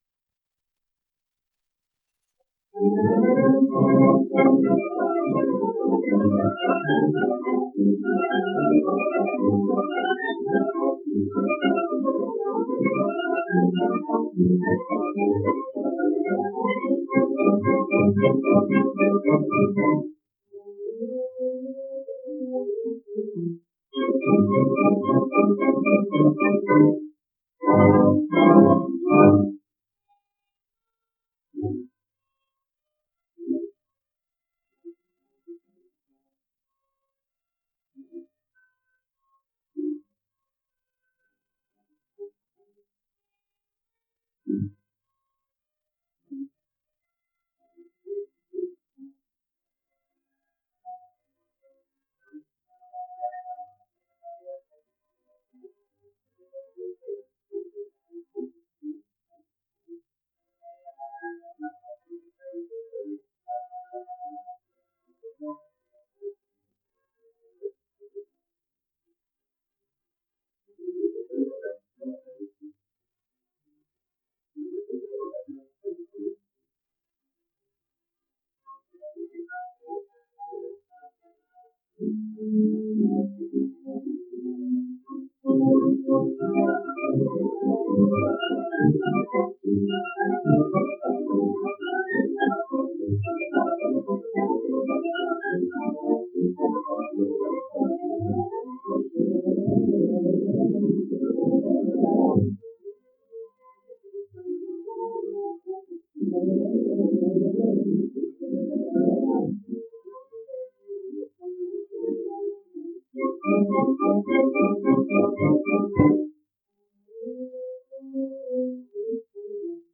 1 disco : 78 rpm ; 30 cm Intérprete